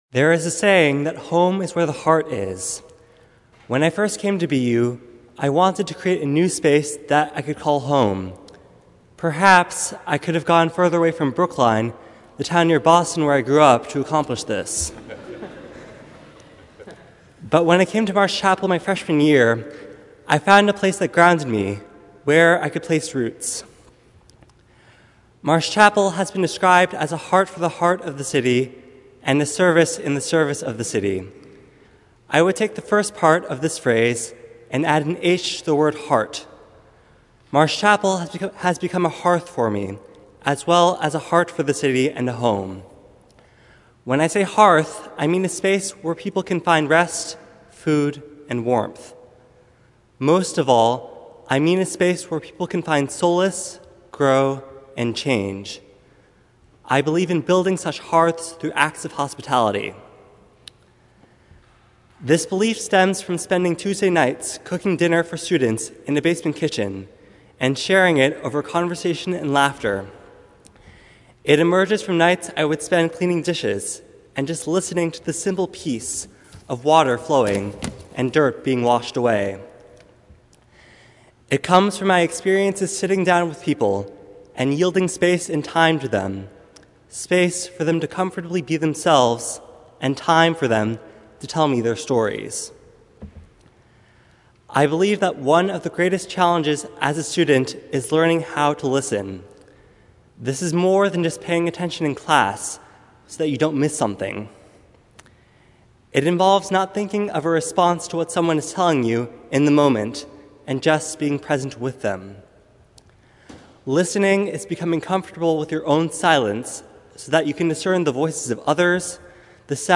Marsh Chapel’s annual “This I Believe” Sunday
Each year, graduating students from any University school or college and from any faith tradition (or no faith tradition) are invited to address the Marsh congregation on the Sunday before Commencement.